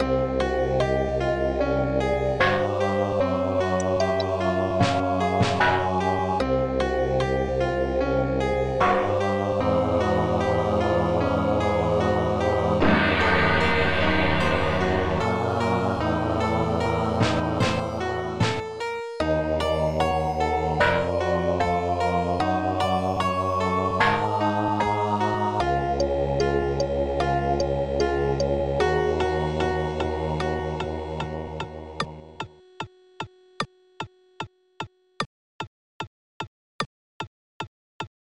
Protracker Module
Gong-o-splash